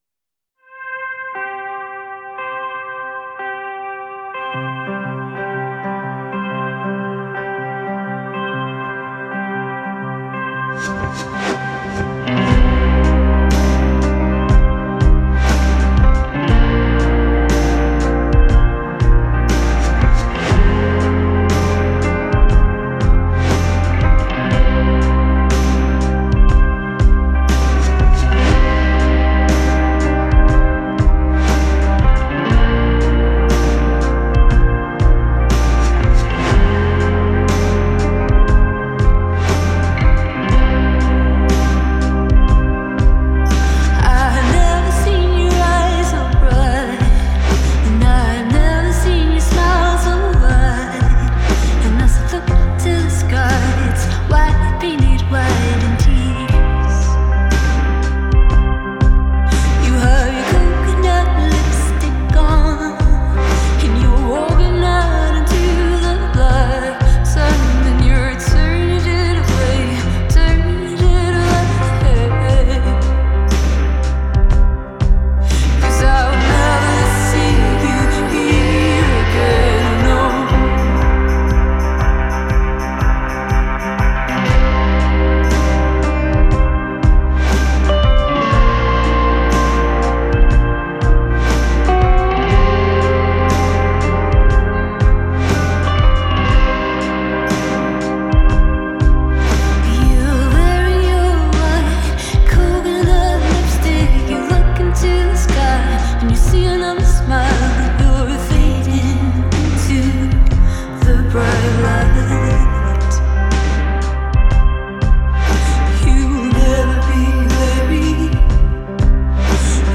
Genre: Indie, Rock, Pop